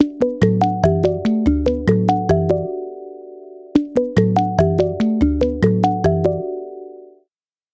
Звуки уведомлений Андроид
Рингтоны для Google Pixel Atria